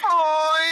flashlighton_grau.wav